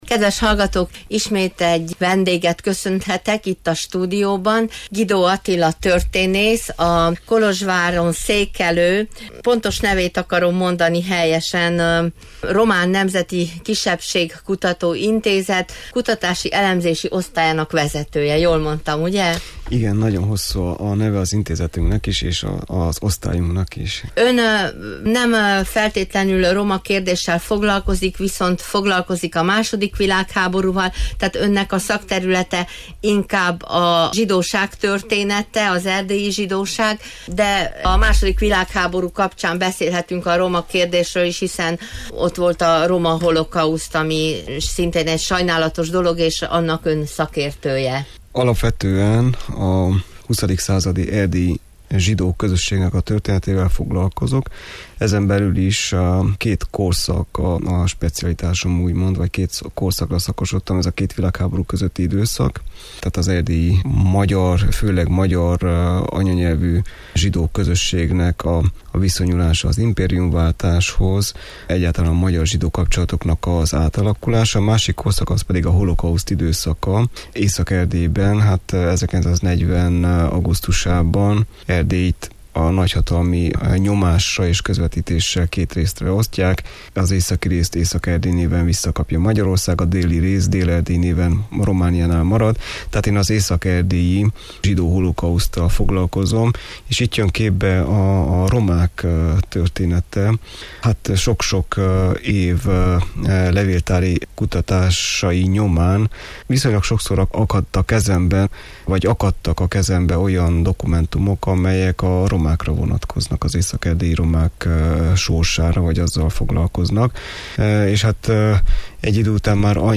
Most ezt a vasárnap reggel sugárzott stúdióbeszélgetést ajánljuk honlapunkra látogató hallgatóink figyelmébe.